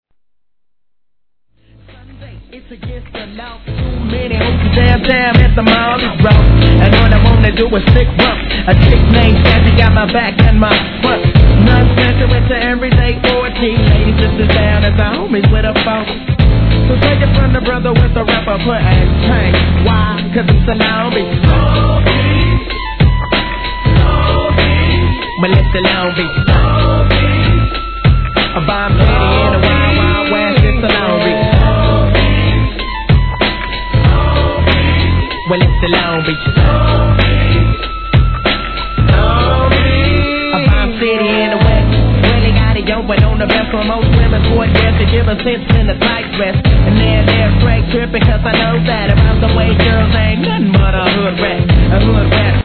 G-RAP/WEST COAST/SOUTH